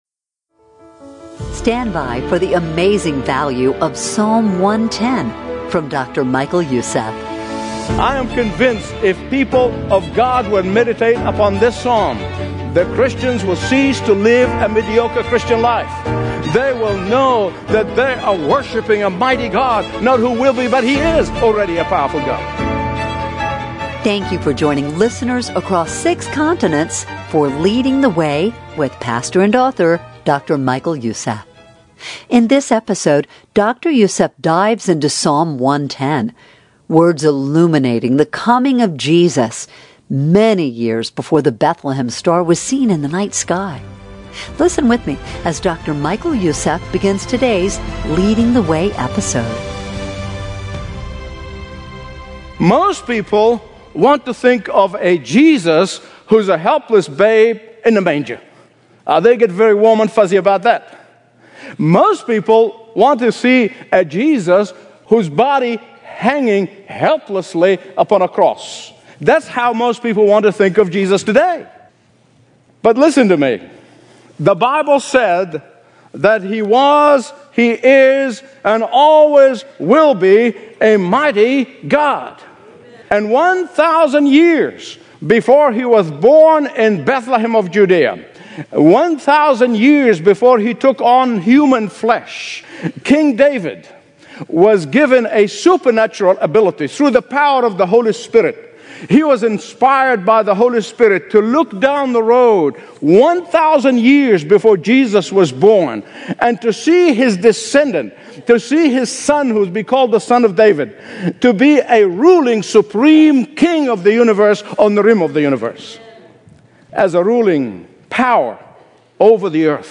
Discover a collection of impactful radio messages